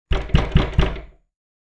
دانلود صدای در چوبی 1 از ساعد نیوز با لینک مستقیم و کیفیت بالا
جلوه های صوتی
برچسب: دانلود آهنگ های افکت صوتی اشیاء دانلود آلبوم صدای کوبیدن در چوبی از افکت صوتی اشیاء